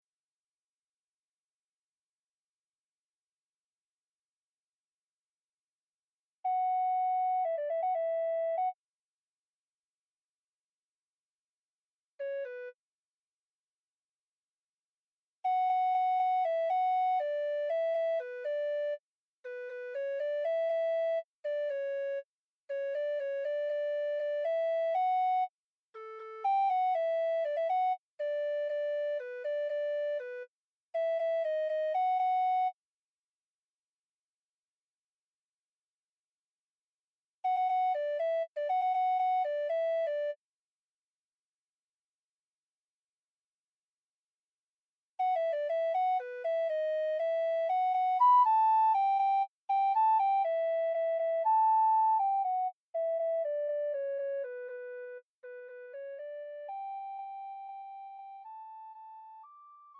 음정 원키
장르 구분 Pro MR